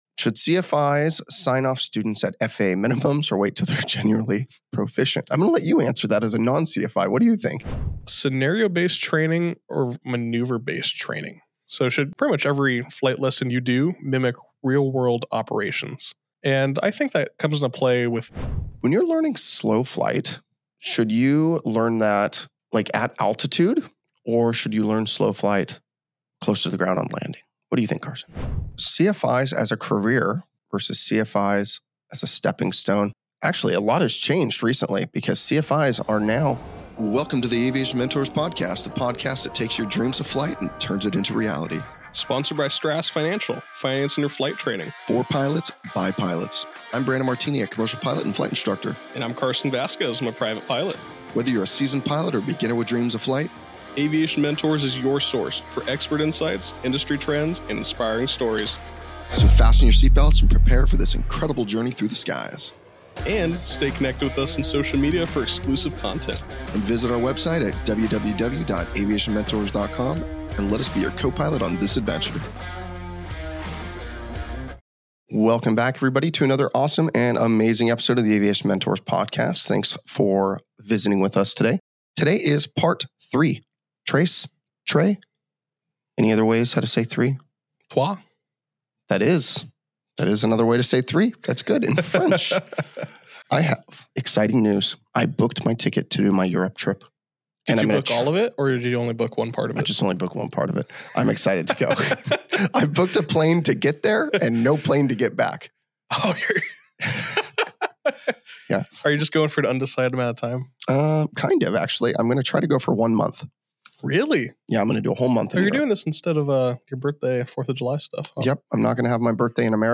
From sneaky gear failures to simulated chaos mid-flight, this episode balances edge-of-your-seat scenarios with big laughs and sharp wisdom. Part 2 brings even more altitude and attitude—just when you thought it couldn’t get any spicier.🌶🚀 We are the Aviation Mentors, a podcast dedicated to creating an amazing space where we aviators can hang out and enjoy a wide range of conversations about anything aviation.